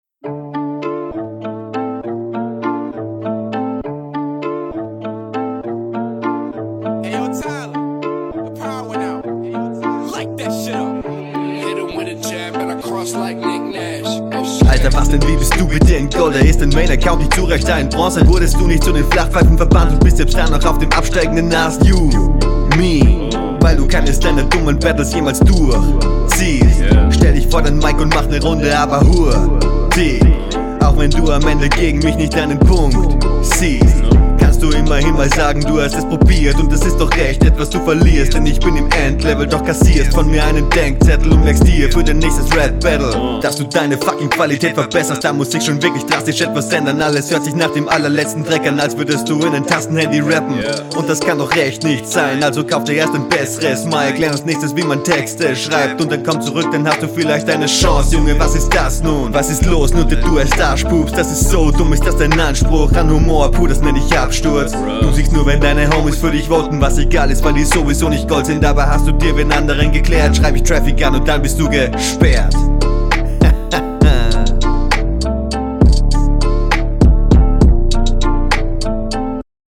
cool cool, Flow ist die ganze Runde über auf nem guten Level, außer mby beim …
voll wacker beat... ich mag die flows :D stimmeinsatz ist halt ein bisschen wenig, aber …